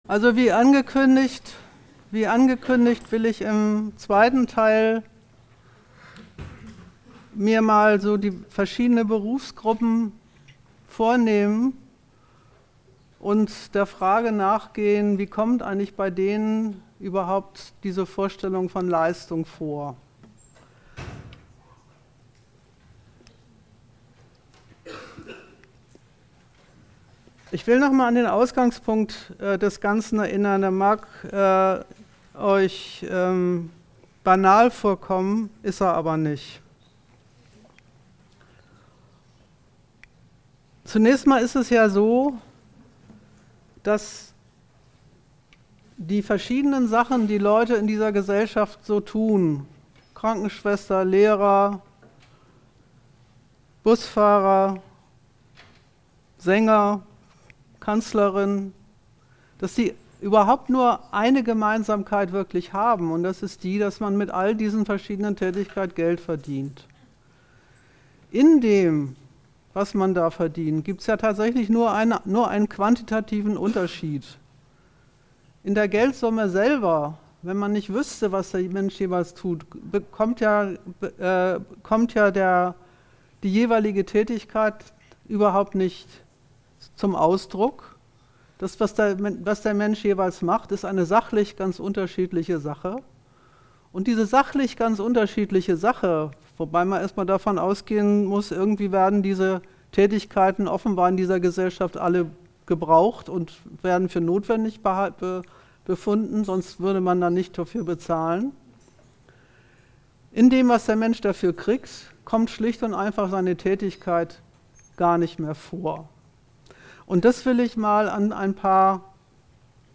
Achtung: Die Störgeräusche am Anfang verschwinden nach etwa einer Minute.